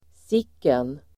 Ladda ner uttalet
sicken pronomen (vardagligt), what [informal]Uttal: [²s'ik:en] Böjningar: sicket, sicknaDefinition: vilkenExempel: sicken snygg kille!